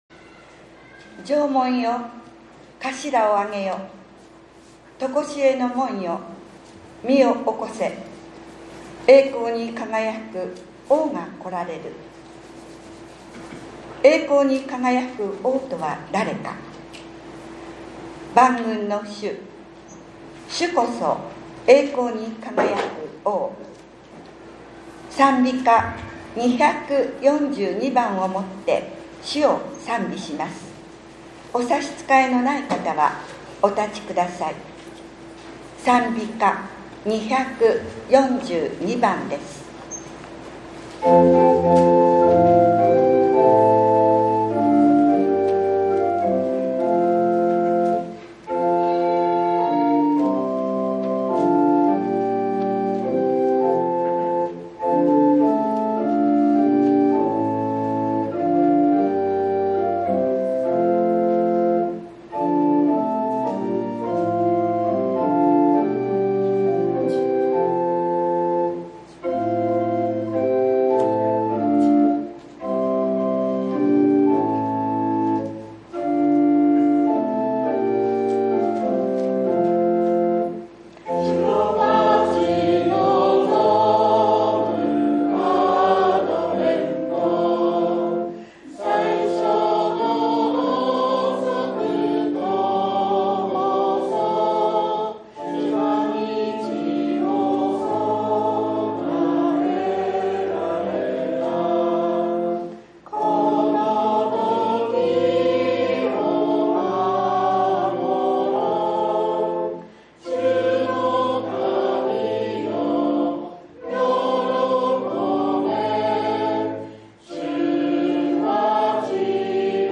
１２月１５日（日）降誕節第３主日礼拝